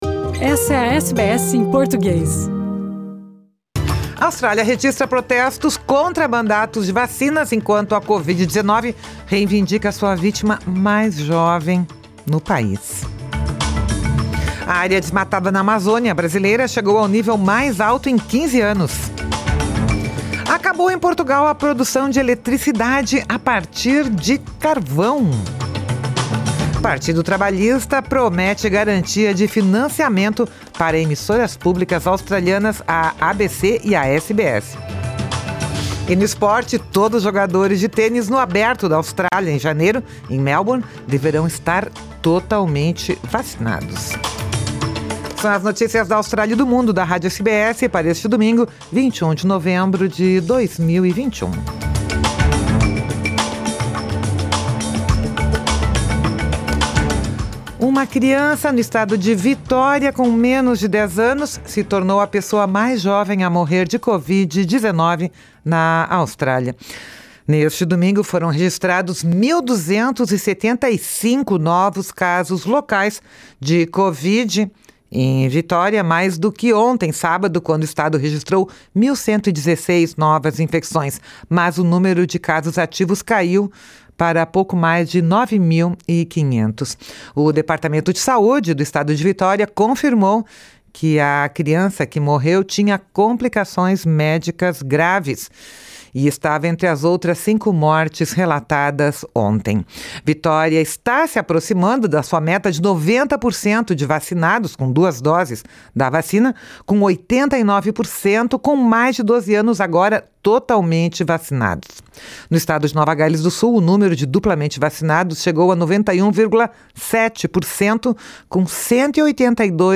São as notícias da Austrália e do Mundo da Rádio SBS para este domingo, 21 de novembro de 2021.